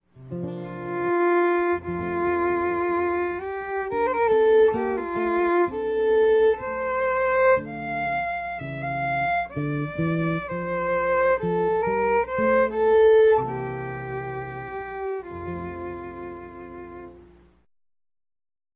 guitarist
a collection of four traditional Irish tunes